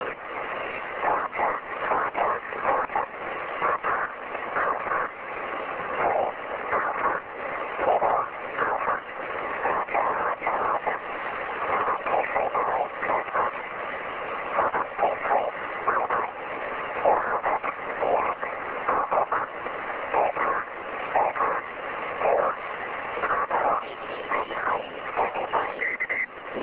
144MHz
SSB Aurora…. 27Kb
ssbaurora.ra